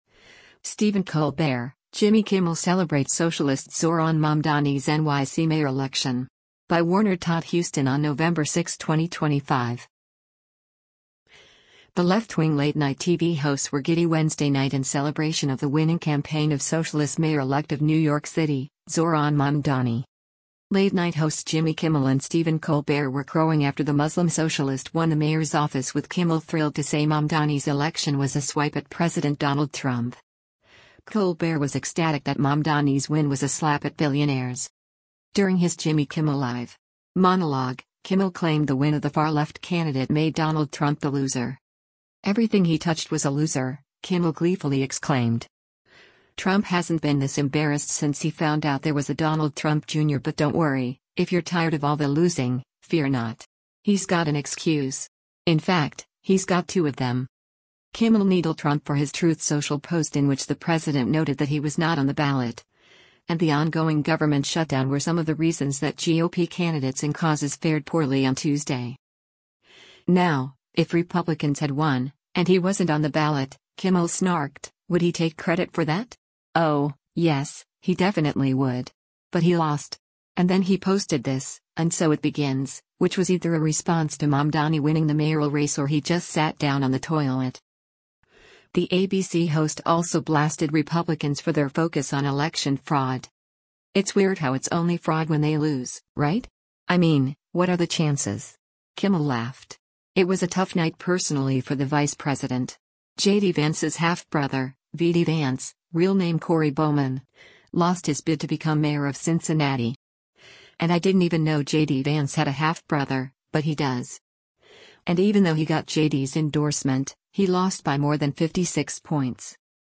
During his Jimmy Kimmel Live! monologue, Kimmel claimed the win of the far-left candidate made Donald Trump the loser.
“Everything he touched was a loser,” Kimmel gleefully exclaimed.